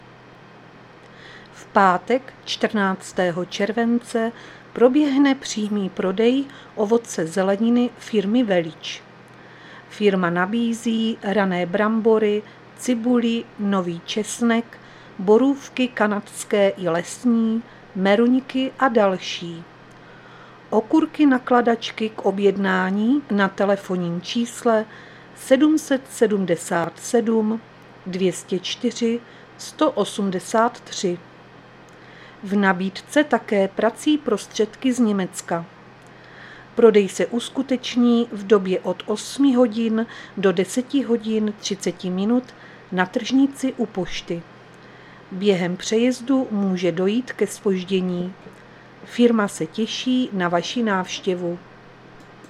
Záznam hlášení místního rozhlasu 12.7.2023